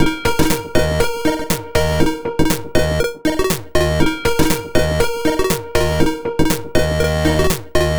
/samples/CHIPSHOP_DELUXE/CHIPSHOP_LOOPS/120_BPM/
ChipShop_120_Combo_D#_03.wav